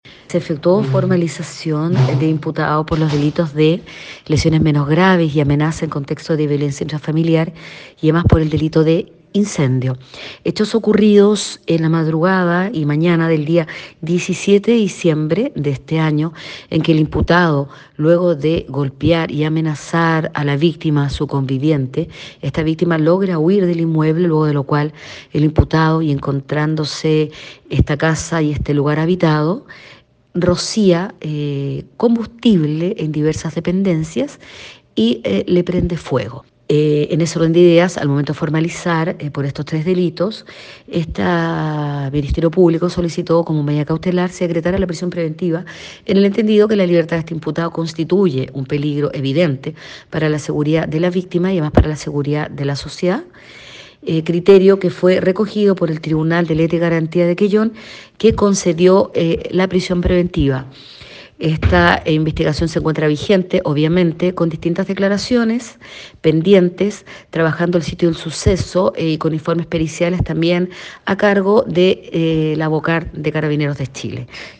Una vez detenido y llevado a audiencia de control de la detención y formalización, se pidió la prisión preventiva, medida cautelar que fue acogida por el Tribunal de Letras y Garantía de Quellón, indicó la persecutora.